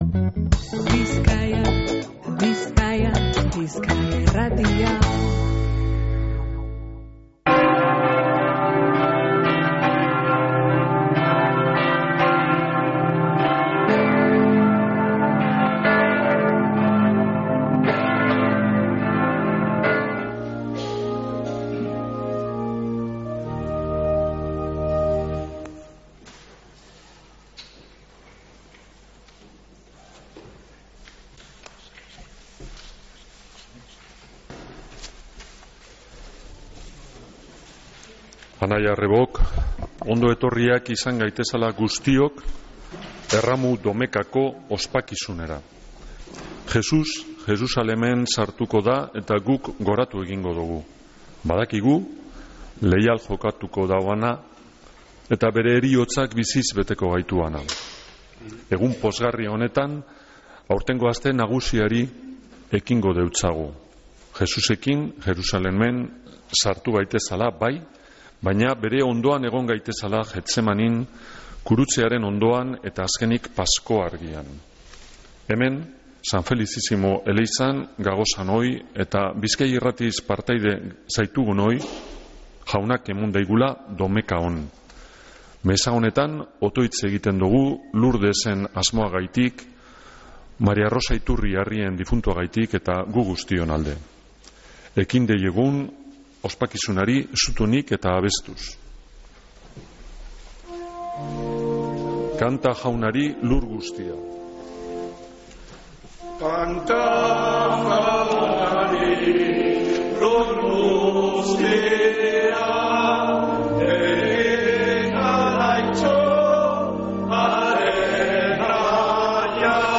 Mezea (25-04-13) | Bizkaia Irratia